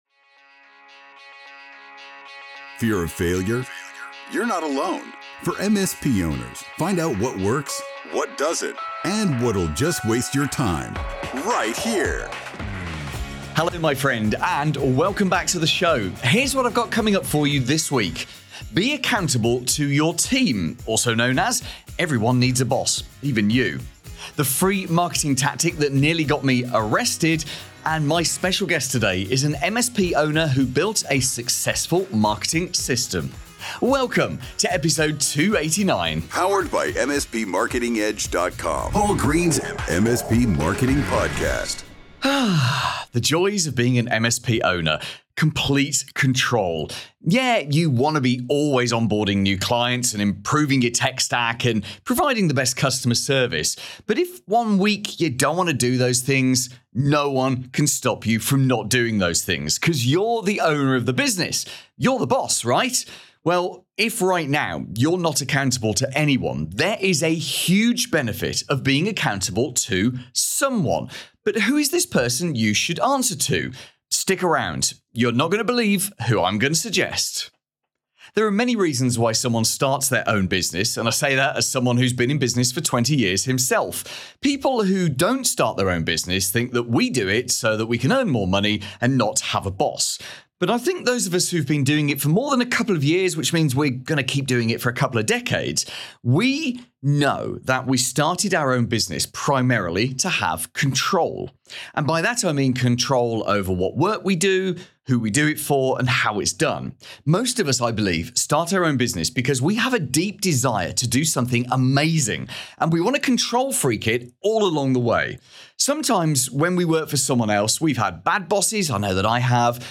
How this MSP built a GREAT marketing system: My guest shares the warts and all story of how she grew her MSP, and how it really took off when she put in place a